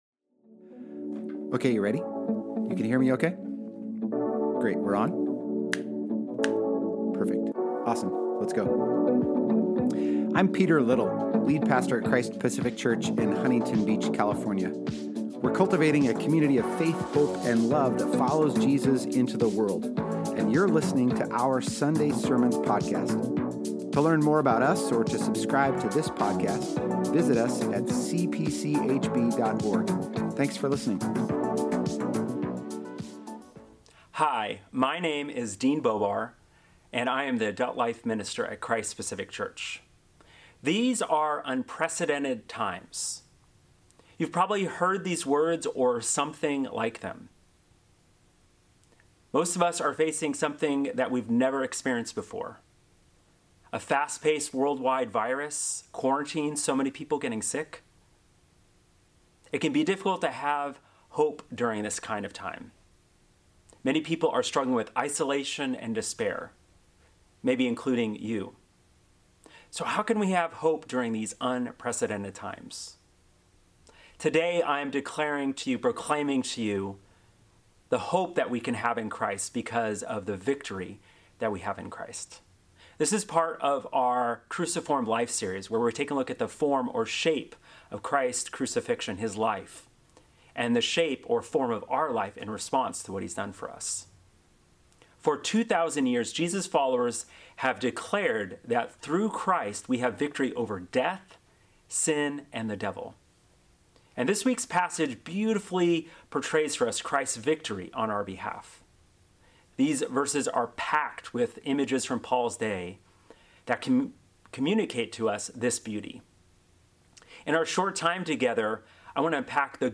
Christ-Pacific-Palm-Sunday-Worhip-Servicemp3.mp3